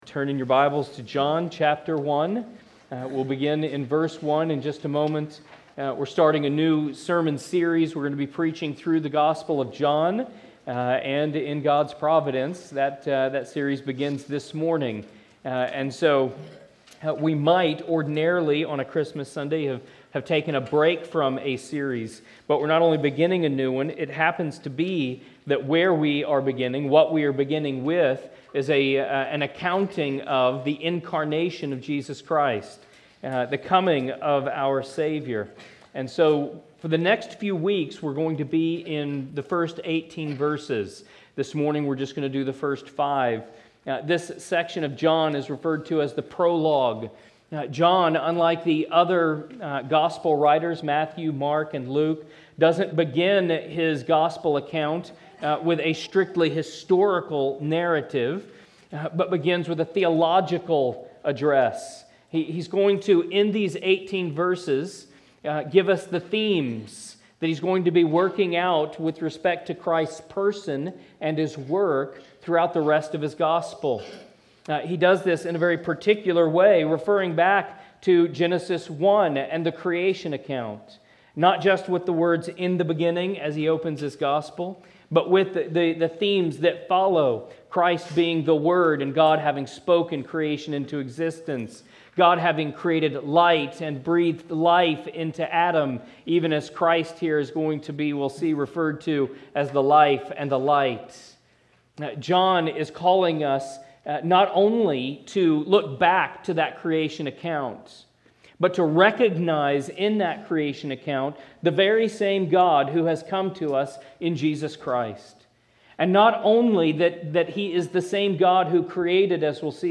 1 Scripture Readings, Morning Worship | Sunday, January 12, 2025